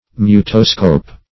Mutoscope \Mu"to*scope\, n. [L. mutare to change + -scope.]